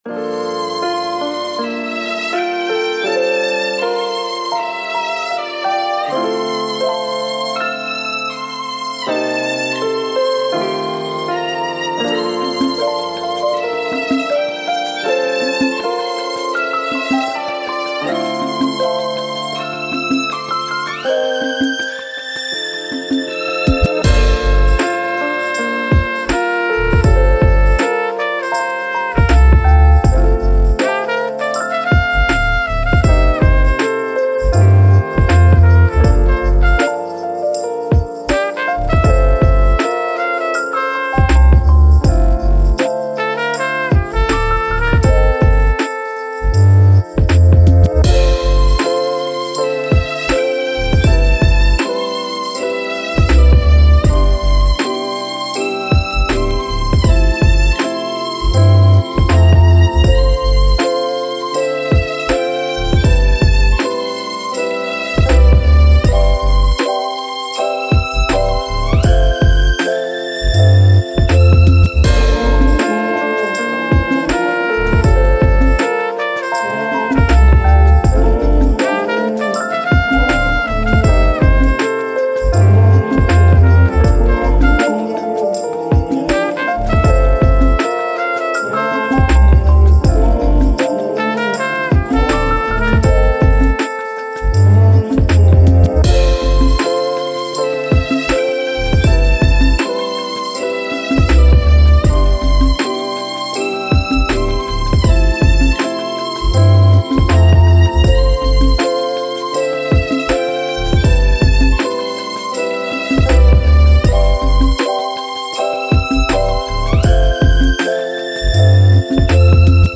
Soul, Strings, Brass, Elegant